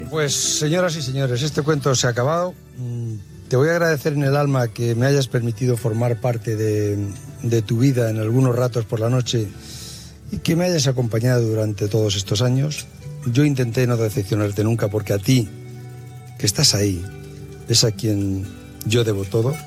Esportiu
FM